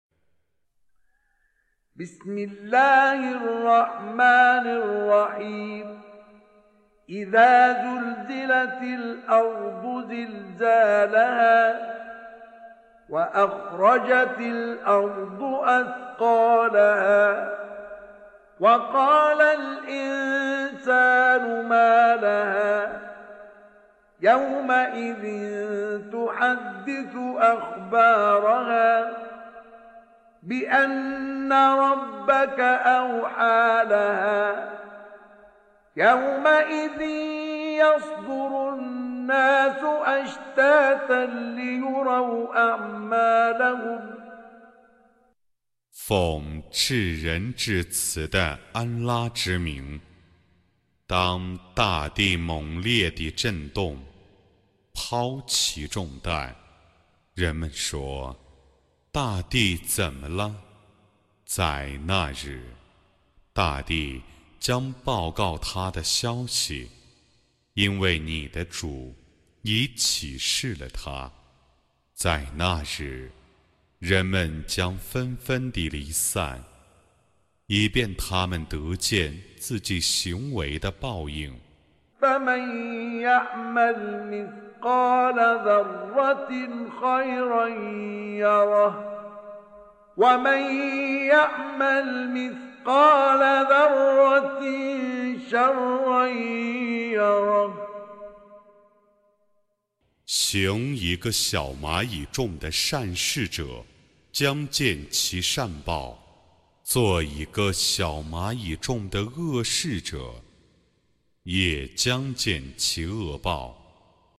Recitation
With Qari Mustafa Ismail